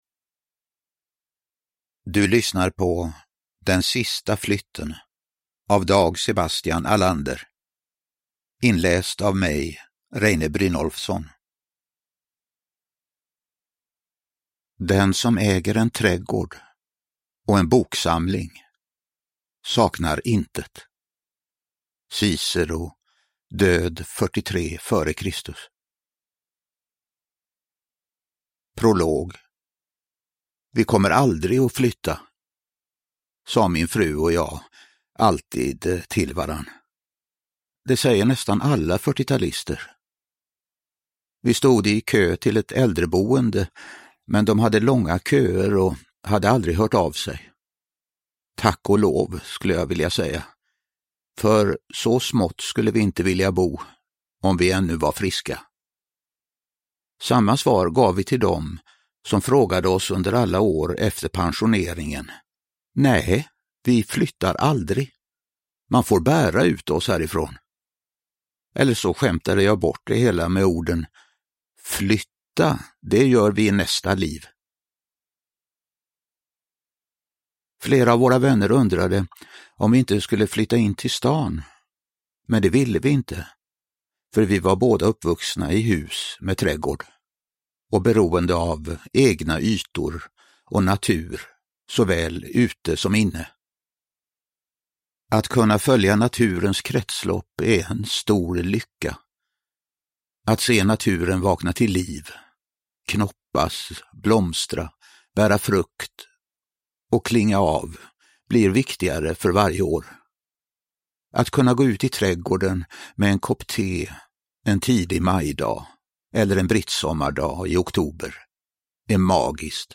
Den sista flytten (ljudbok) av Dag Sebastian Ahlander